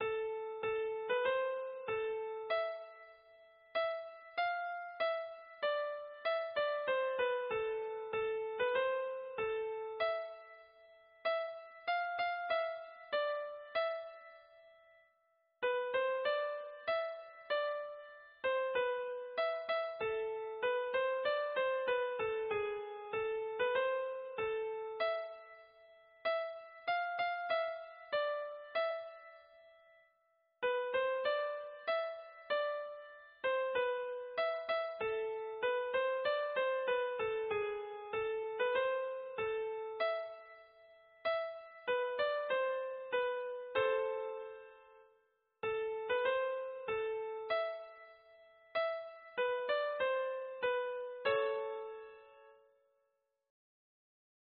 Ténor
soun_tres_rei_tenor.mp3